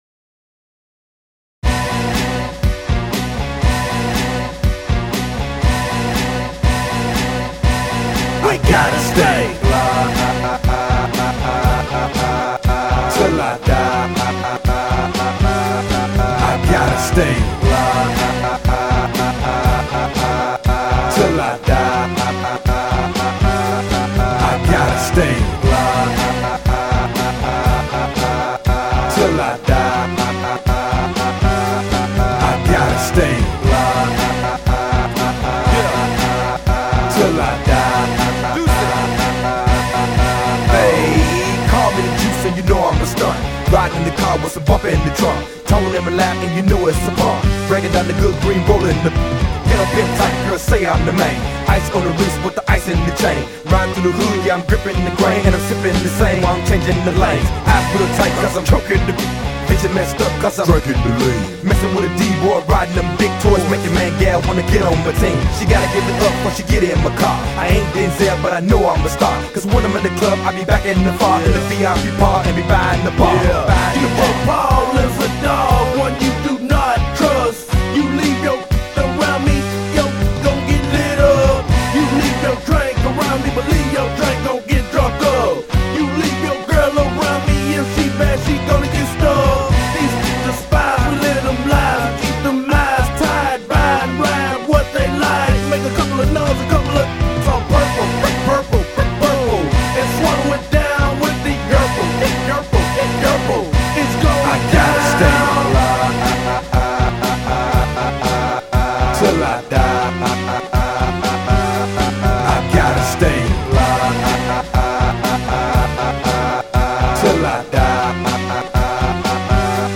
Drum & bass
RnB
Rap